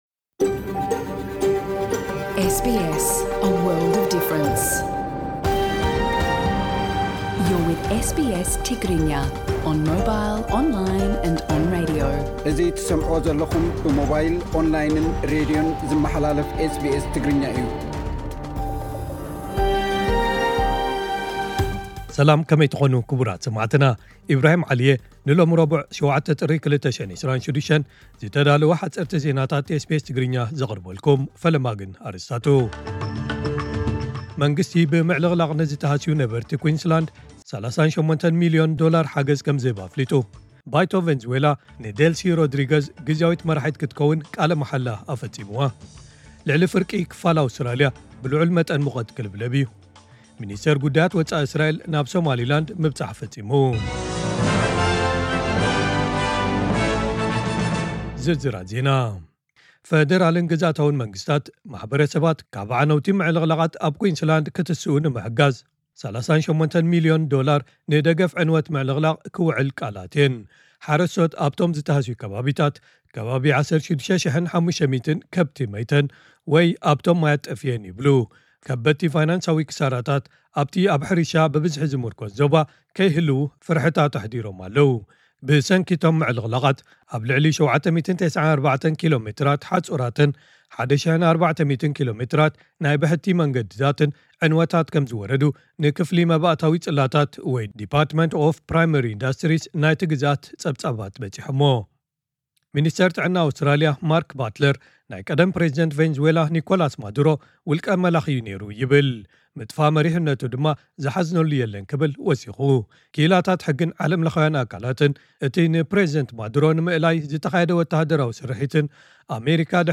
ዕለታዊ ዜና ኤስቢኤስ ትግርኛ (07 ጥሪ 2026)